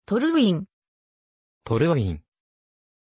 Pronunciation of Torwin
pronunciation-torwin-ja.mp3